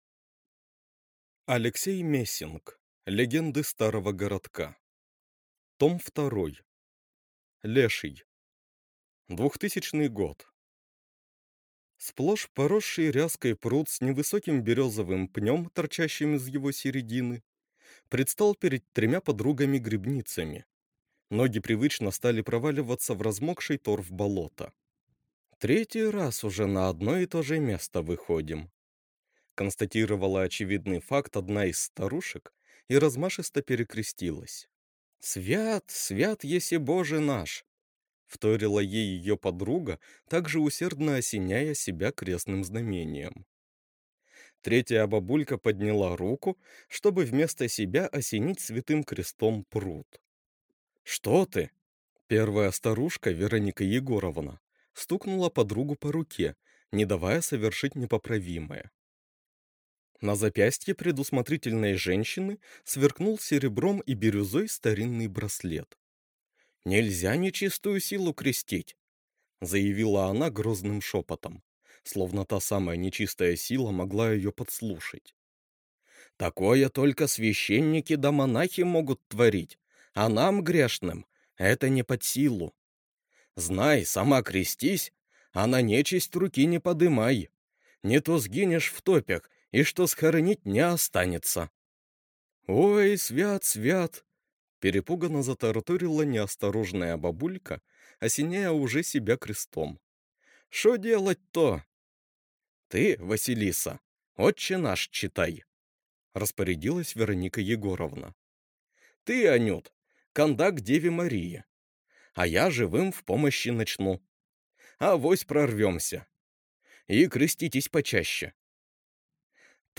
Аудиокнига Легенды старого городка. Том 2 | Библиотека аудиокниг